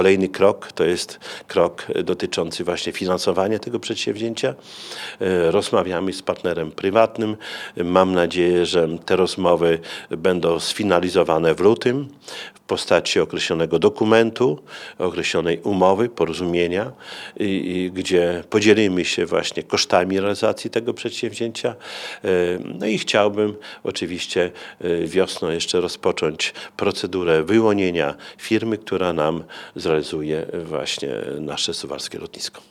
Równocześnie miasto prowadzi rozmowy z lokalnym przedsiębiorcą, w sprawie współfinansowania pasa startowego.- Dzięki takiej pomocy oraz wsparciu Urzędu Marszałkowskiego łatwiej będzie udźwignąć finansowy ciężar inwestycji – dodaje prezydent.